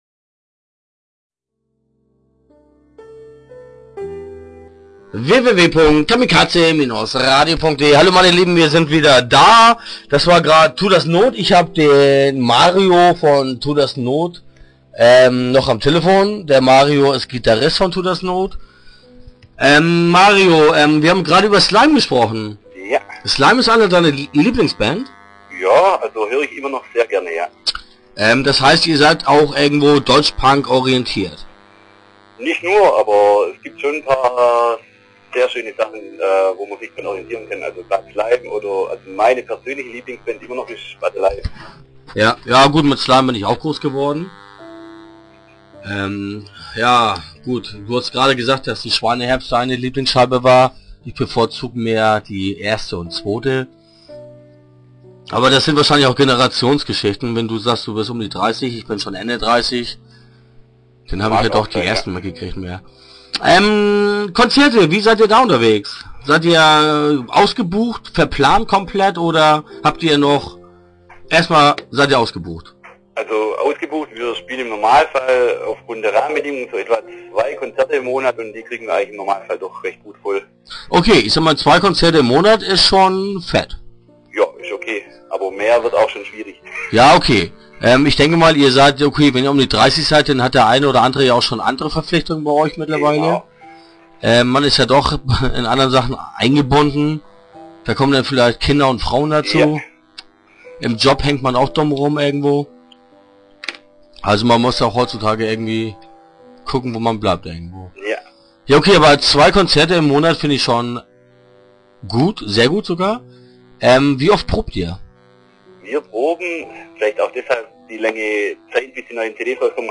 Start » Interviews » Tut das Not
spricht am Telefon mit